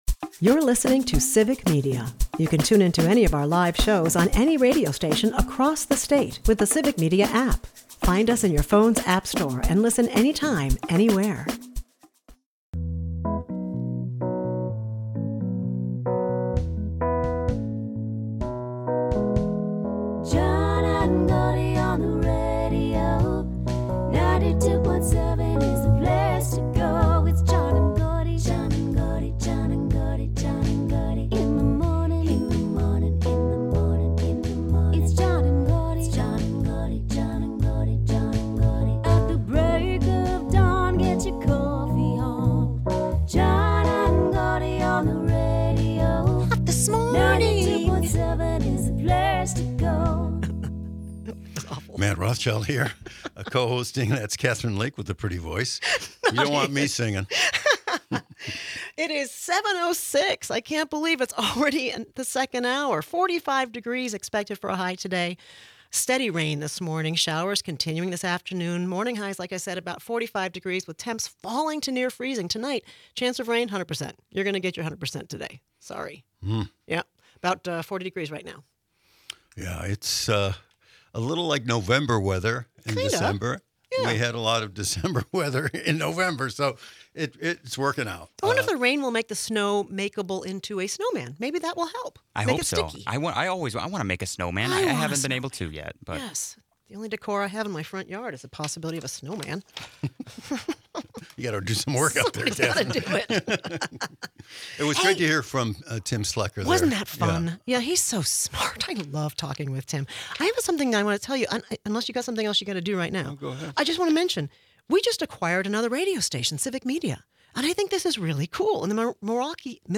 A poetic interlude adds a reflective note to the lively conversation.